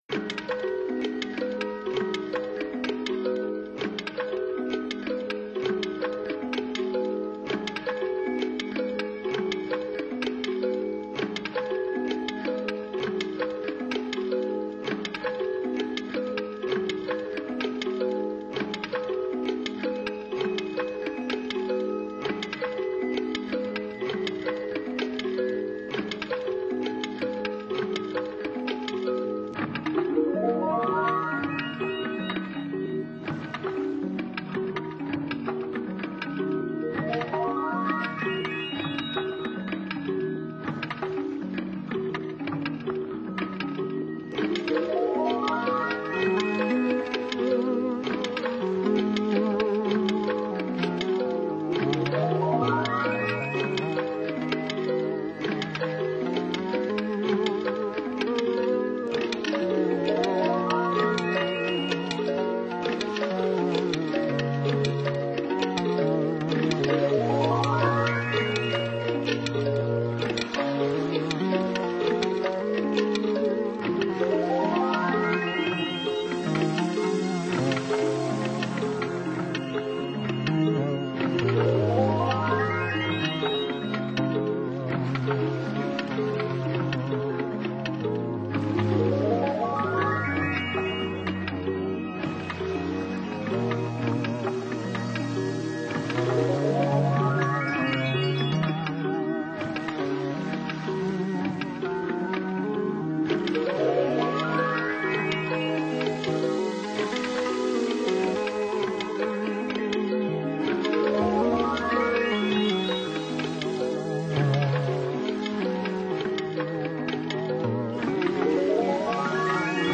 在戴上耳机的一瞬间你就 陷入了完全的音乐氛围中，真正的360度环绕声！